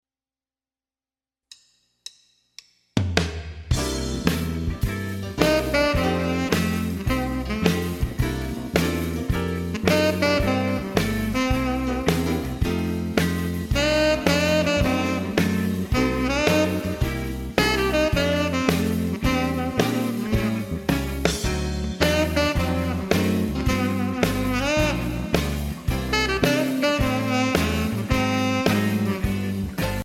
Voicing: Guitar and m